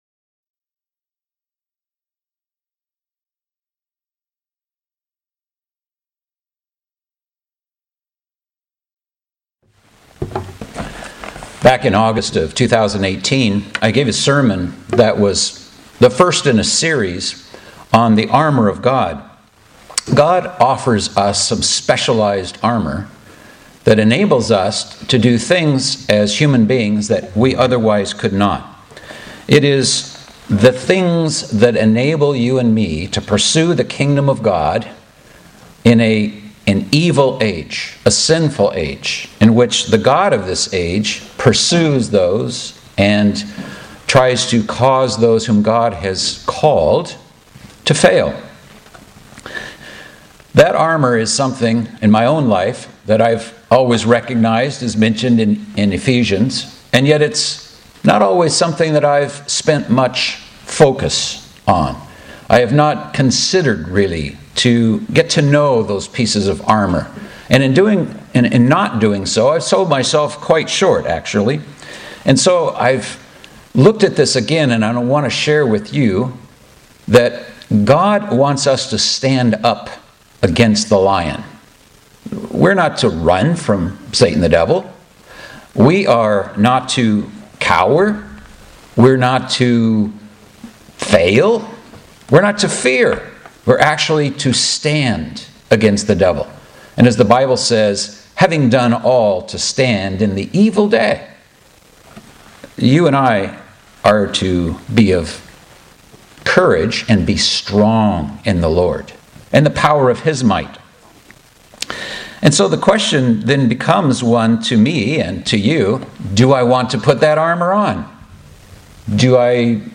The 2nd sermon in the series on the Armor of God.